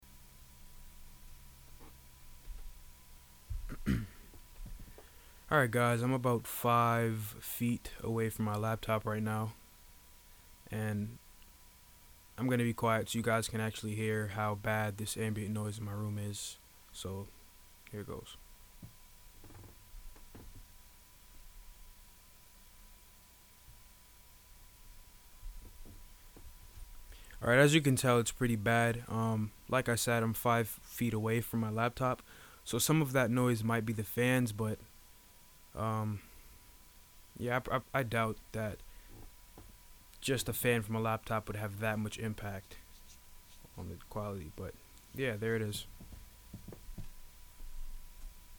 Ridiculous ambient noise help
Hey everyone, I currently use a mxl v67g, a behringer xenyx 802 mixer, and a behringer u control uca202 to transfer mic audio through the mixer and into the computer. However, the amount of ambient noise my mic picks up is DISGUSTING.